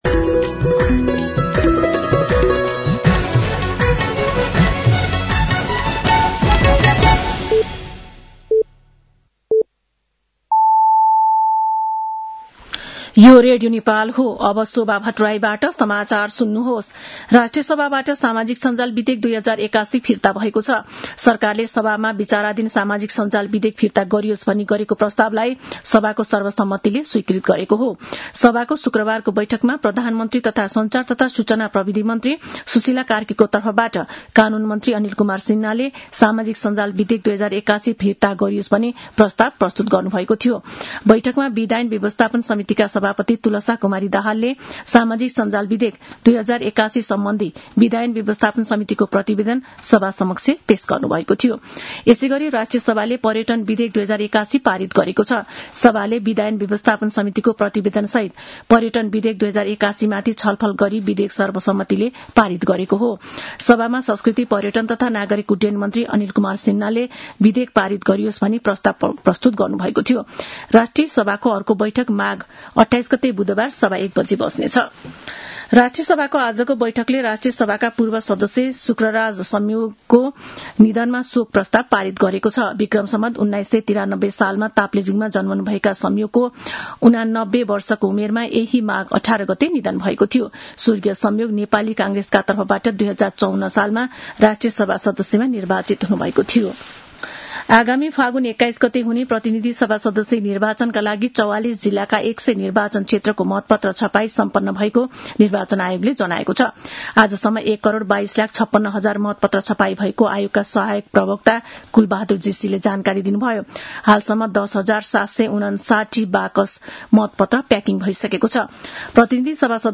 साँझ ५ बजेको नेपाली समाचार : २६ माघ , २०८२
5.-pm-nepali-news-1-4.mp3